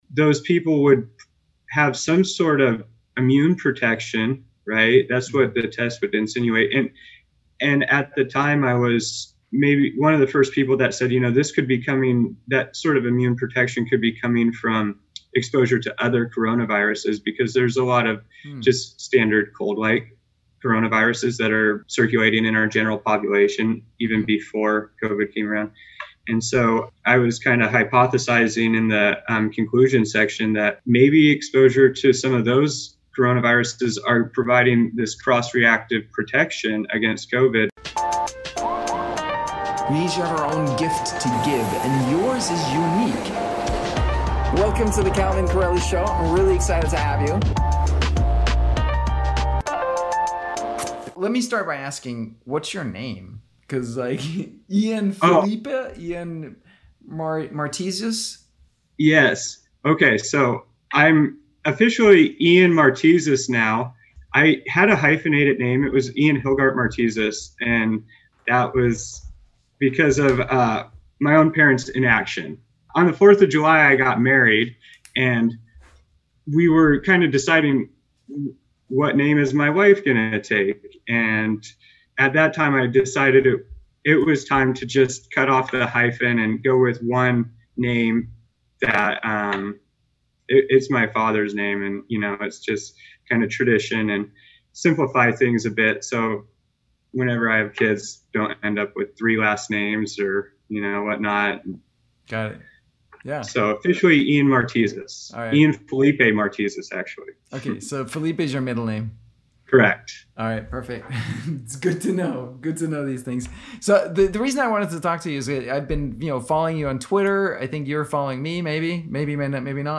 Truths and Lies of Covid-19 Testing An Interview